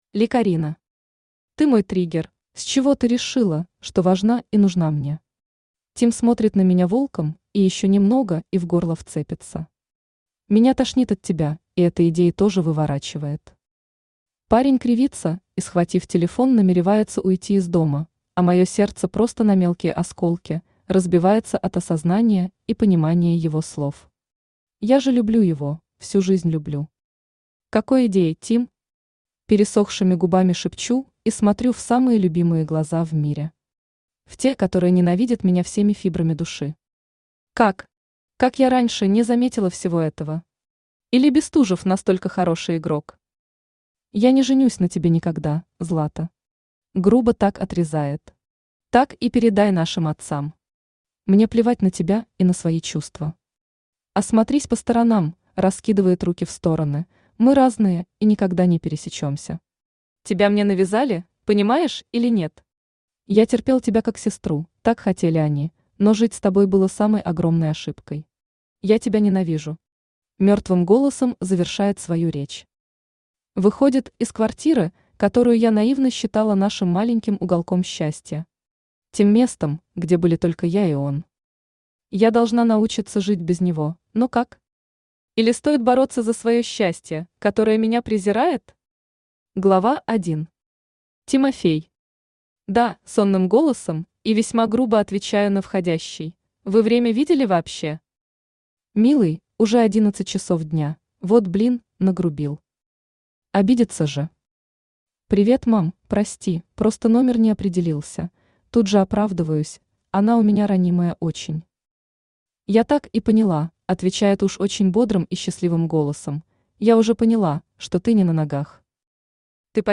Аудиокнига Ты мой триггер | Библиотека аудиокниг
Aудиокнига Ты мой триггер Автор Ли Карина Читает аудиокнигу Авточтец ЛитРес.